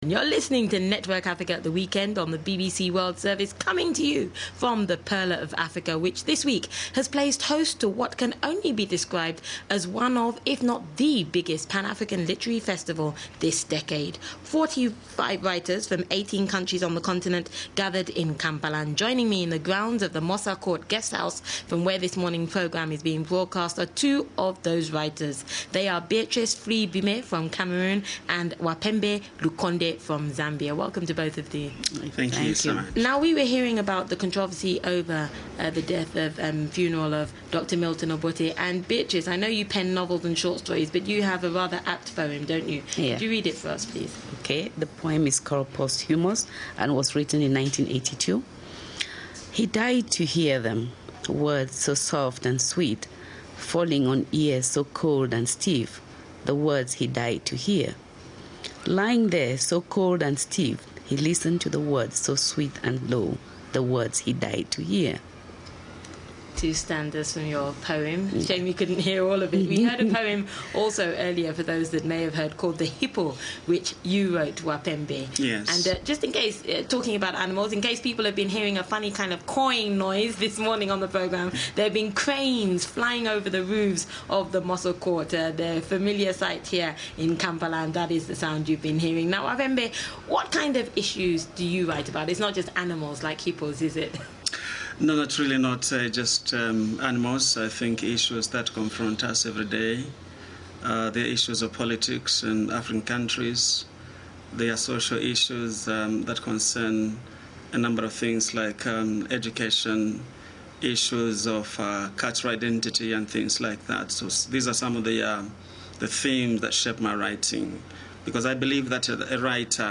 BBC World Service Interviews at the Beyond Borders Festival
The following interviews were broadcast on BBC Network Africa at the Weekend on BBC World Service to audiences across the African continent between October 2005 and March 2006.